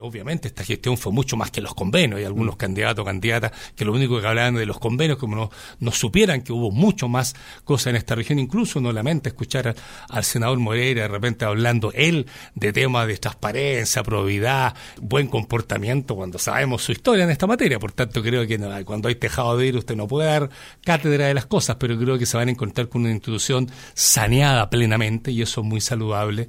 En ese contexto, es que en su visita a los estudios de Radio Bío Bío, el gobernador de Los Lagos, Patricio Vallespín, no desaprovechó la oportunidad para referirse al senador de oposición.